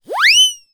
Звуки поскальзывания